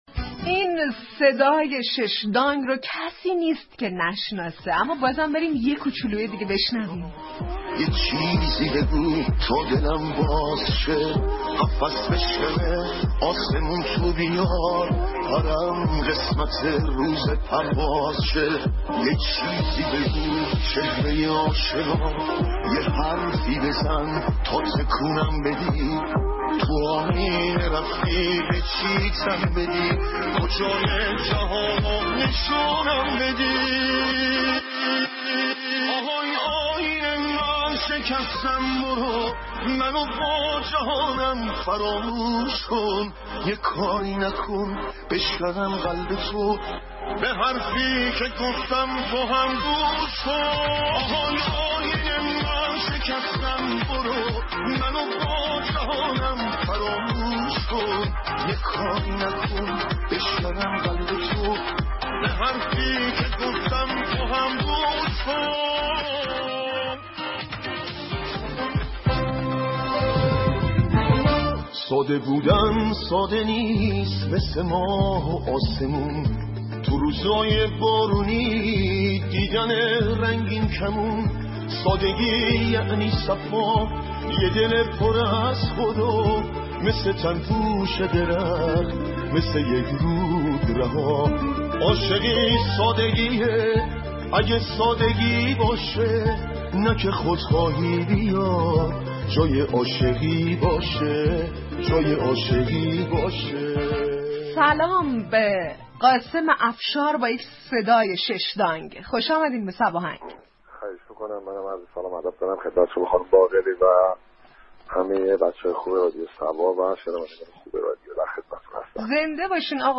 قاسم افشار در گفتگو تلفنی برنامه صباهنگ درباره راز ماندگاری آثار خوانندگان هم نسل خودش توضیح داد.